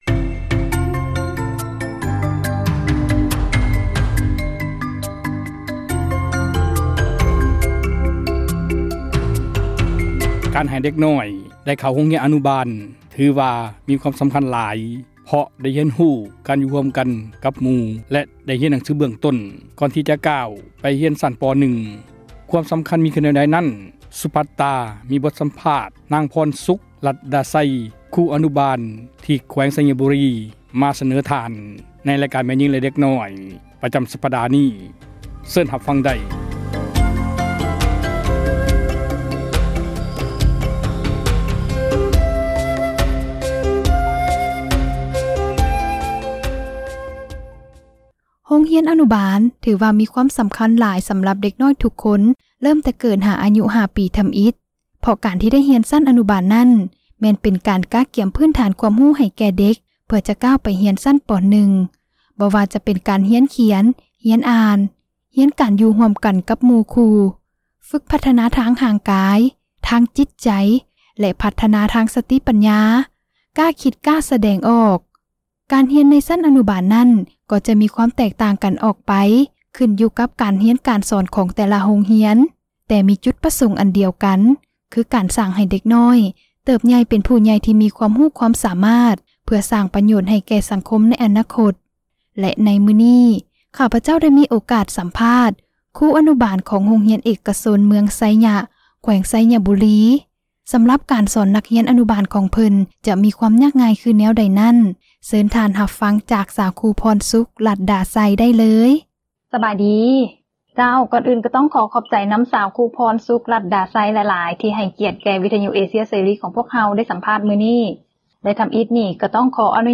F-teacher ສັມພາດ ຄຣູ ອະນຸບານ ຂອງໂຮງຮຽນເອກຊົນ ເມືອງໄຊຍະ